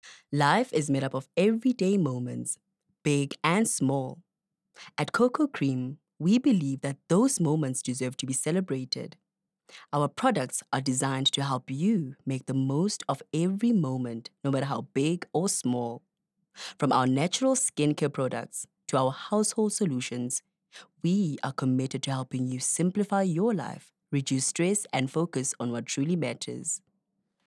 confident, conversational, Formal, friendly, informative, sharp, youthful
Soft Sell.